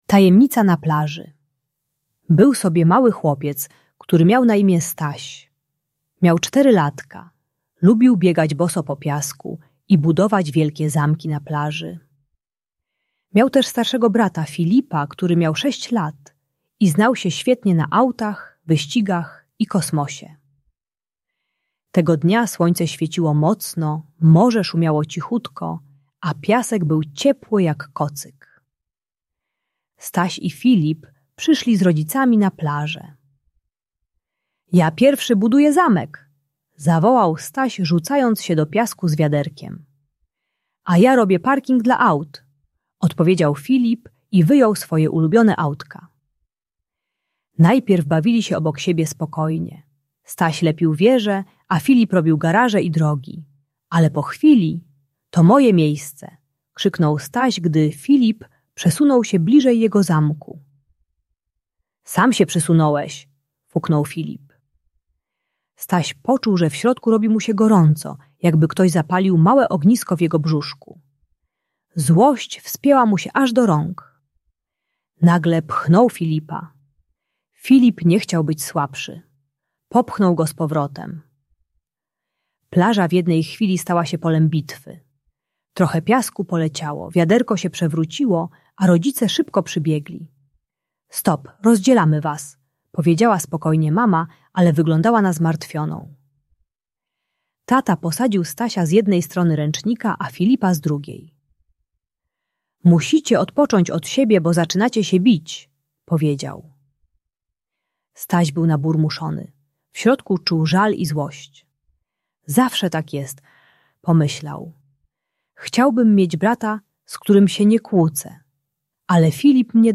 Tajemnica na plaży - Rodzeństwo | Audiobajka